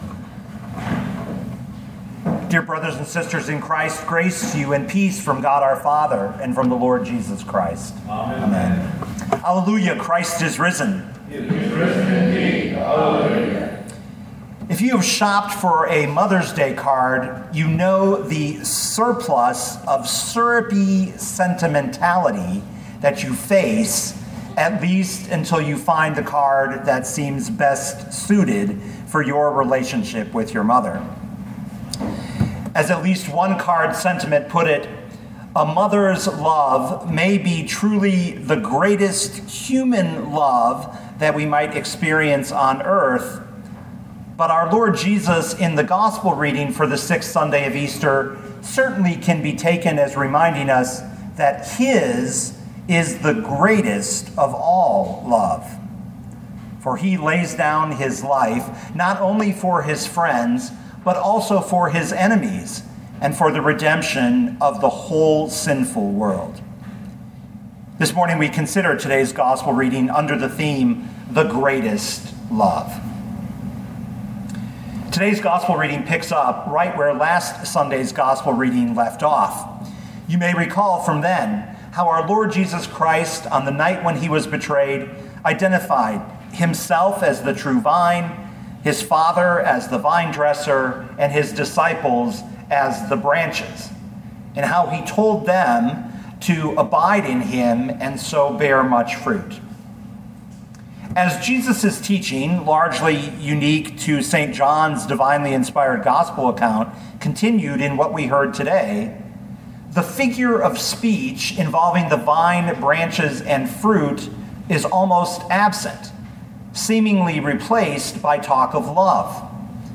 2021 John 15:9-17 Listen to the sermon with the player below, or, download the audio.